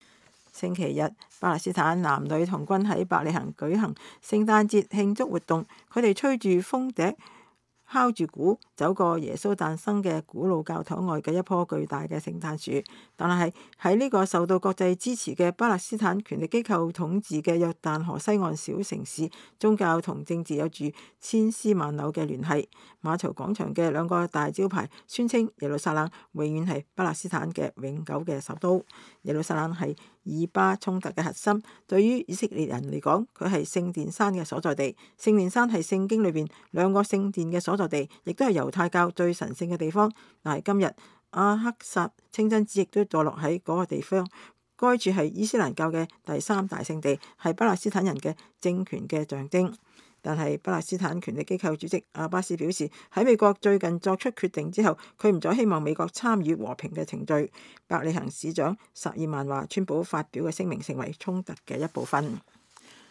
民眾12月24日聖誕前夕在伯利恆舉行聖誕節慶祝活動。
他們吹著風笛敲著鼓，走過耶穌誕生的古老教堂外的一棵巨大的聖誕樹。